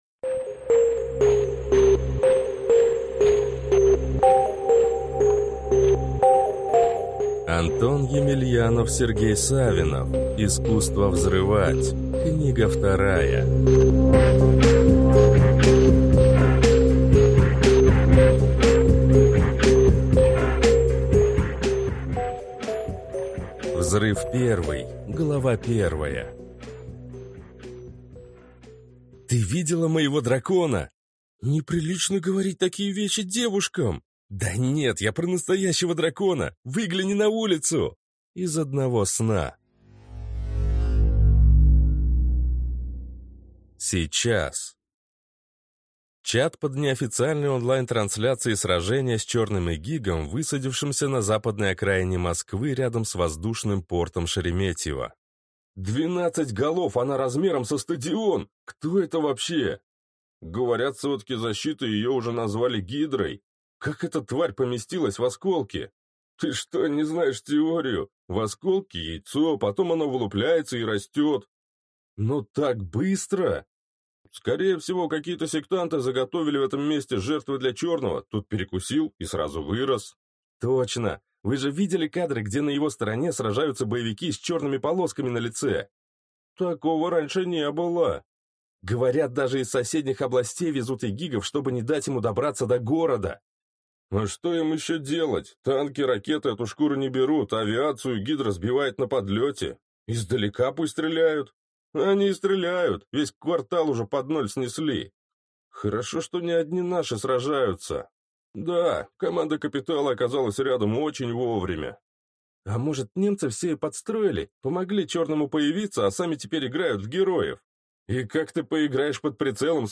Аудиокнига Искусство взрывать. Книга 2 | Библиотека аудиокниг